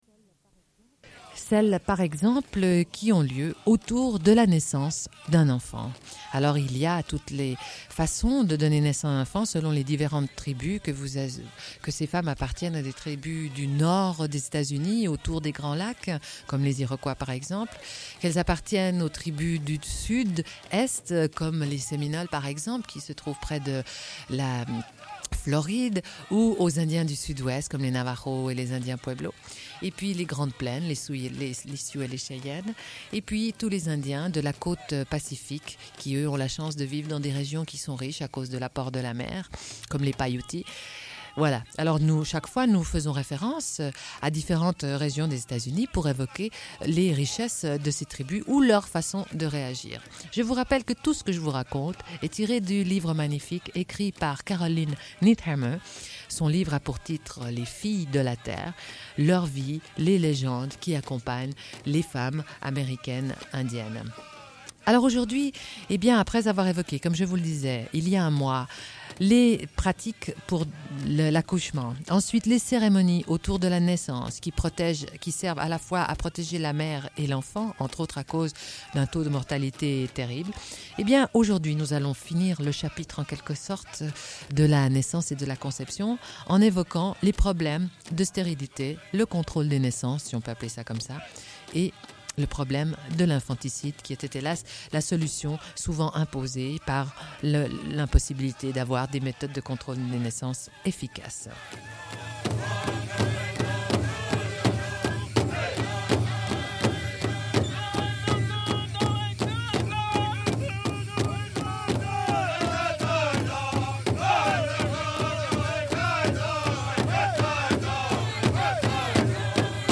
Une cassette audio, face B00:28:58